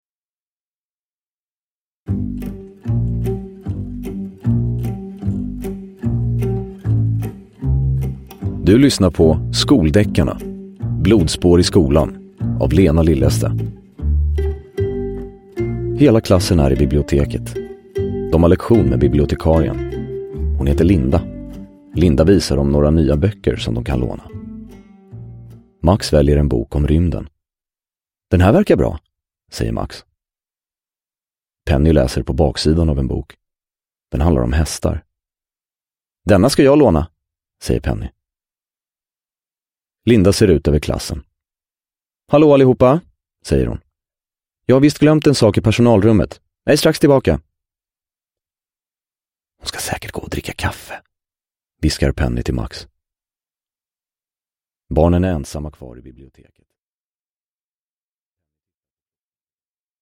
Blodspår i skolan – Ljudbok
Uppläsare: Anastasios Soulis